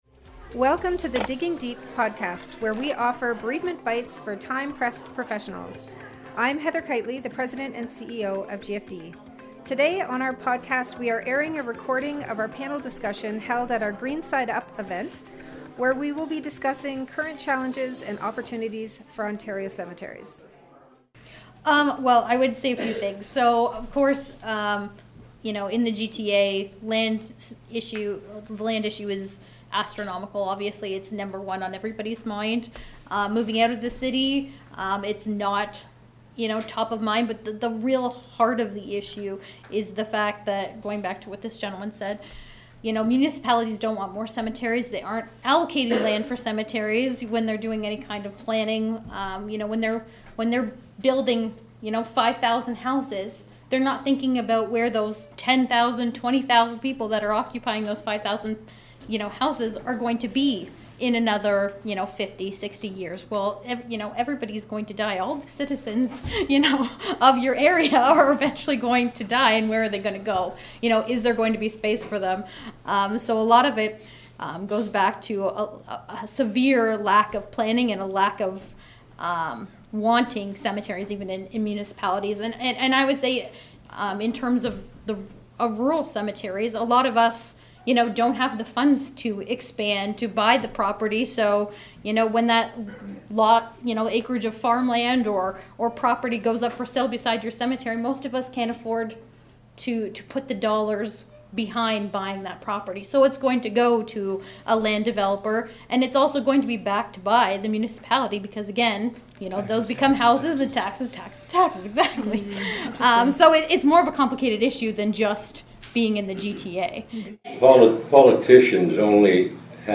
In this insightful conversation